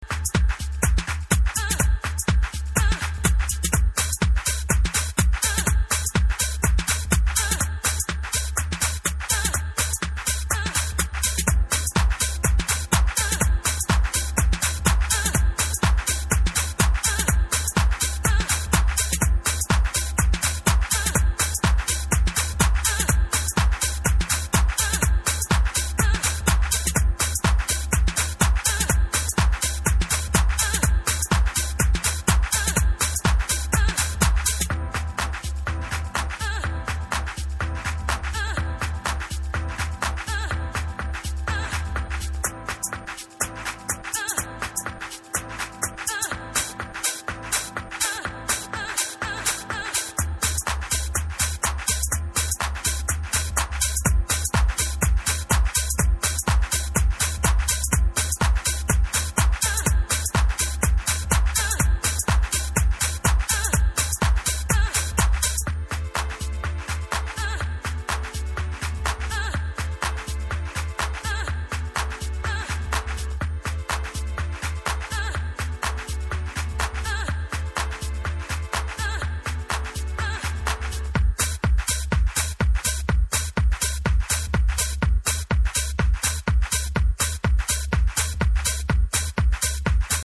Nice udated Detroit House cuts.